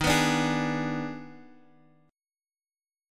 D#M7sus4#5 chord